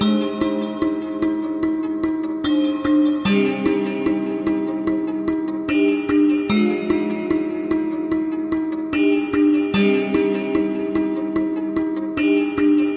描述：Just a throw away Notes go: F,C,D,A,D,C Link Work
标签： 148 bpm Trap Loops Bells Loops 2.18 MB wav Key : Unknown
声道立体声